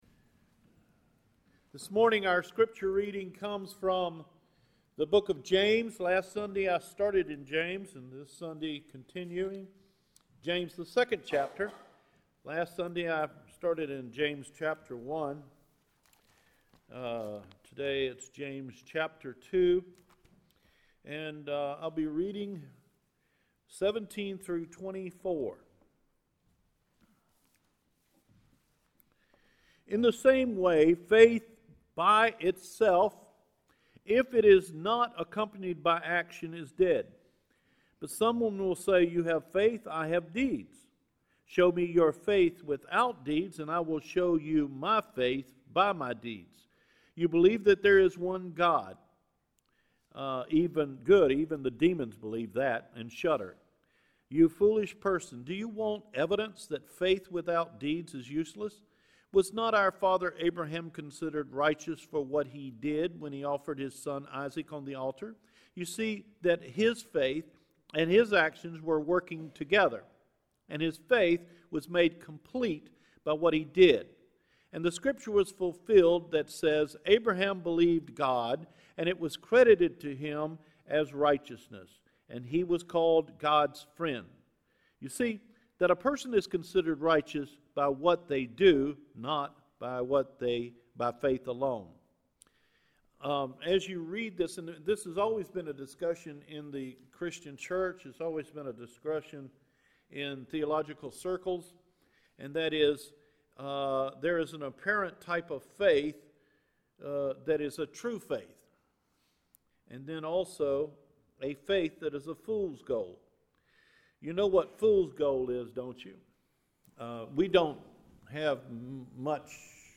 What is Faith Without Action? – February 11, 2018 Recorded Sermon